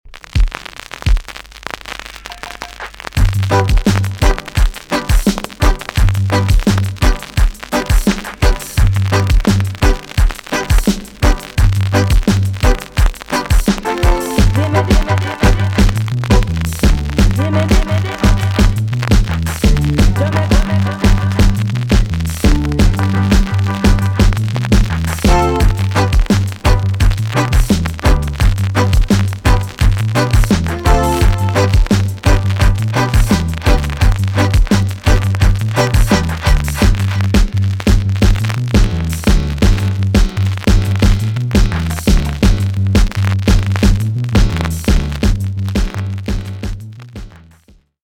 TOP >80'S 90'S DANCEHALL
B.SIDE Version
VG ok 全体的にチリノイズが入ります。